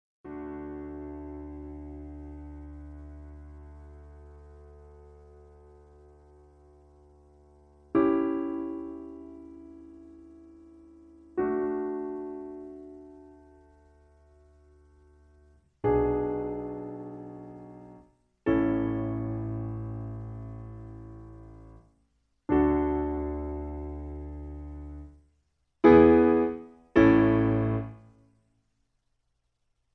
In D. Piano Accompaniment